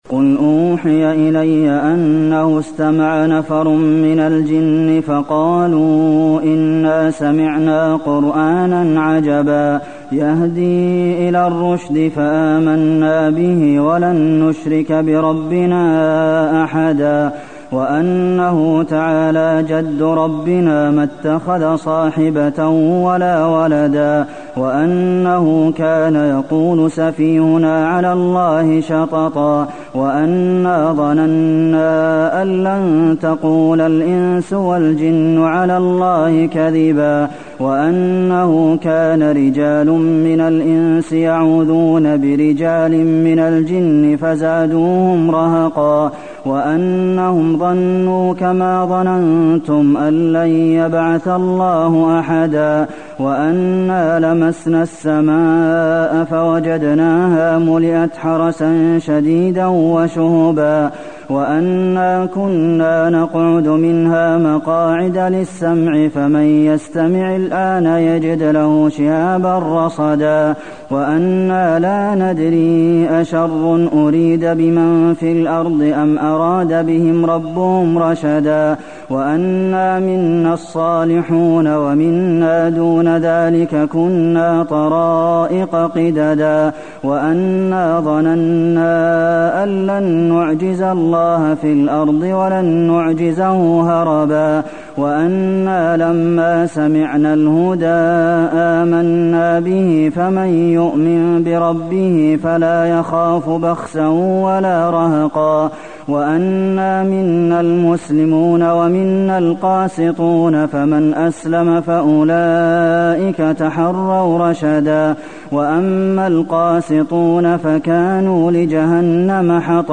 المكان: المسجد النبوي الجن